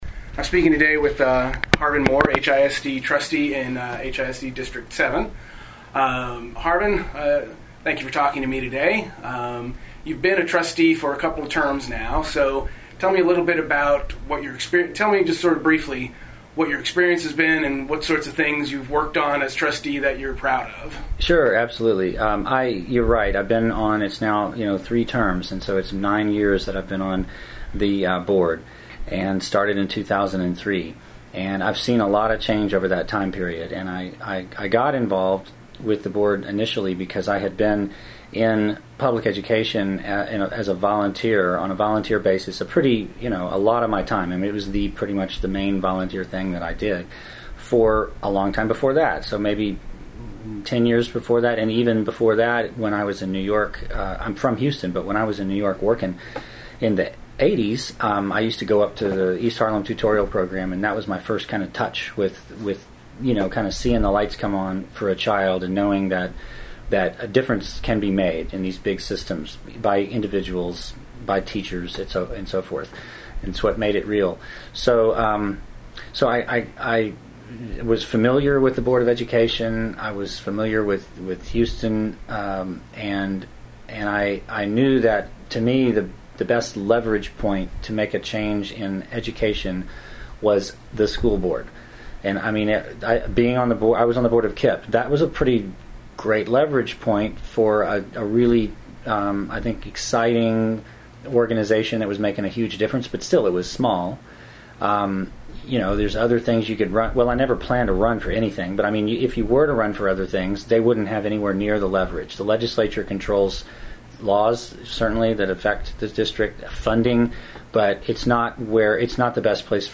Interview with Harvin Moore | Off the Kuff